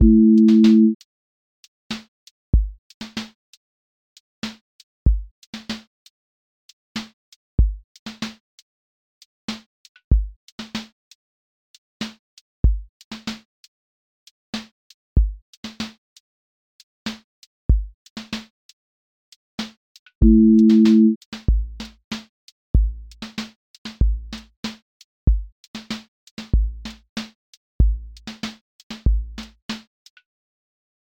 QA Listening Test r&b Template: rnb_pocket
r&b pocket with warm chord bed
• voice_kick_808
• voice_snare_boom_bap
• voice_hat_rimshot
• voice_sub_pulse
• tone_warm_body